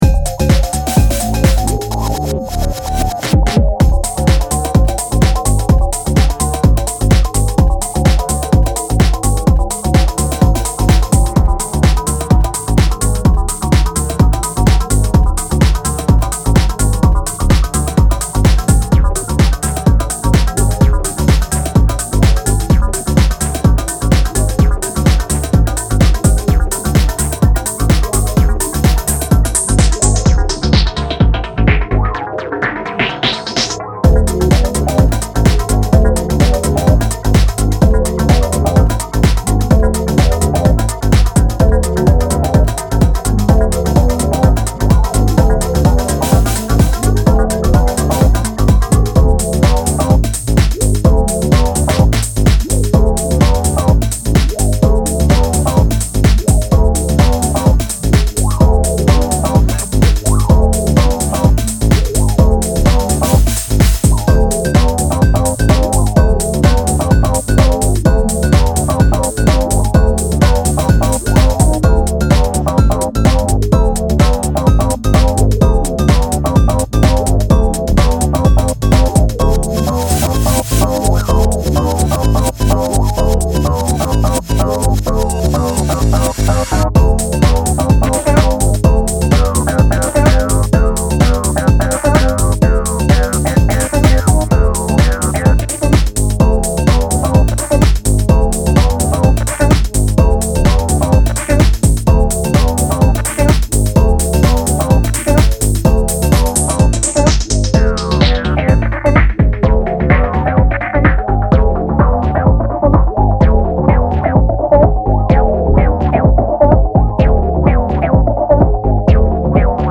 電化パーカッションやモジュラーシンセでサイケデリックなピークタイムを持続させる